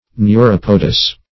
Meaning of neuropodous. neuropodous synonyms, pronunciation, spelling and more from Free Dictionary.
neuropodous.mp3